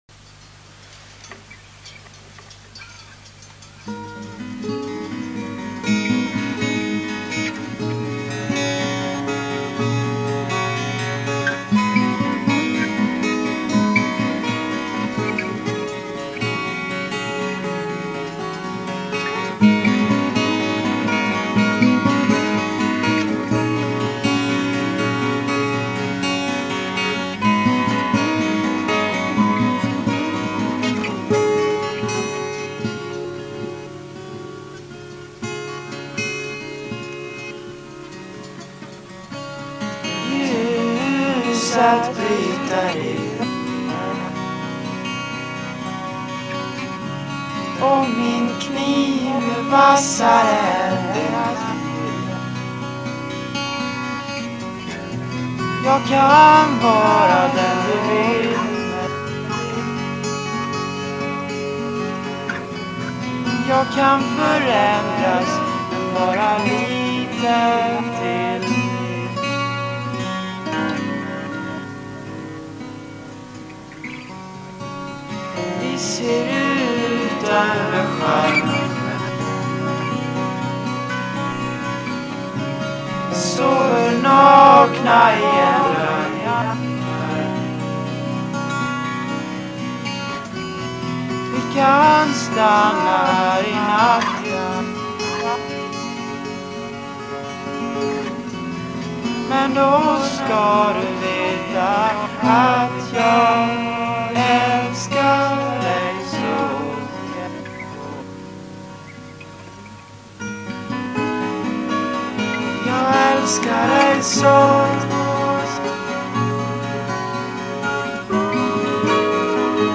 Är ett svenskt popband som sjunger låtar på svenska.